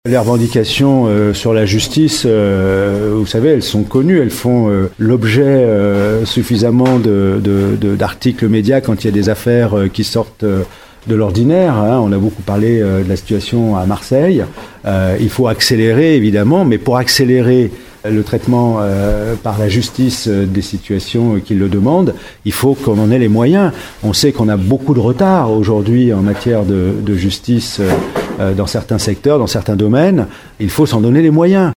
Le 1er congrès de FO justice s’est tenu au centre Azureva de Ronce-les-Bains.
Yves Veyrier qui appelle le gouvernement à donner les moyens nécessaires aux agents de la justice :